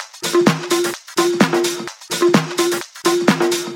VEH1 Fx Loops 128 BPM
VEH1 FX Loop - 15.wav